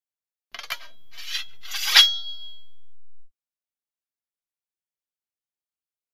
Sword: Remove From Sheath; Light Metal Clangs And A Shing With Ring Off Of Sword Being Drawn Incrementally. Medium Perspective.